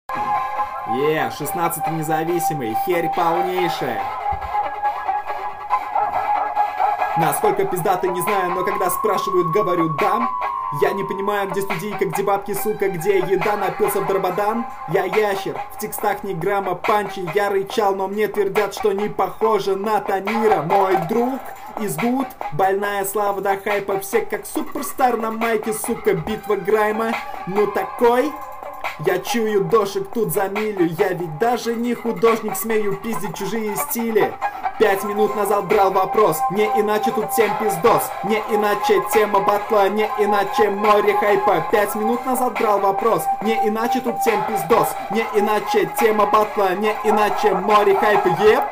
Зато живость есть.